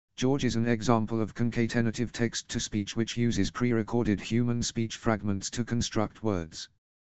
Microsoft George is an example of Concatenative text to speech which uses the same initial process as Sam, but utilizes pre-recorded human speech, rendering the audio fragments for each phoneme to construct a word.